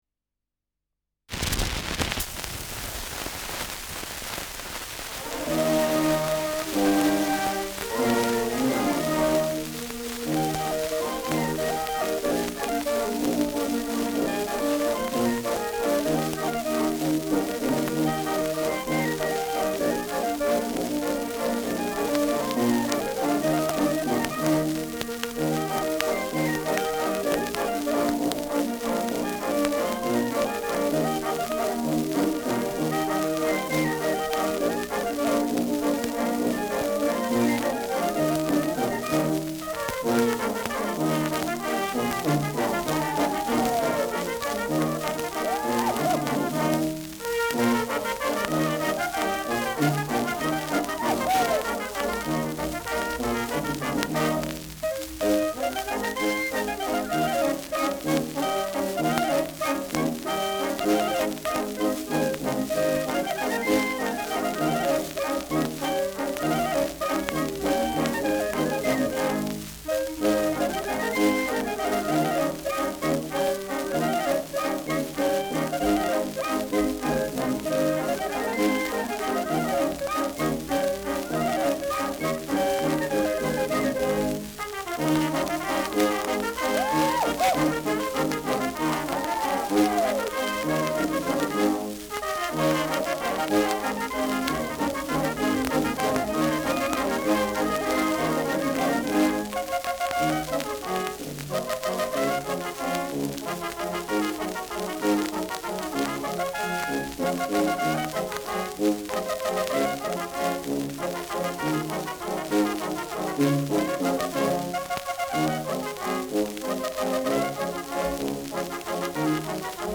Schellackplatte
Starkes Grundrauschen : Gelegentlich leichtes bis stärkeres Knacken
Kapelle Lang, Nürnberg (Interpretation)
[Nürnberg] (Aufnahmeort)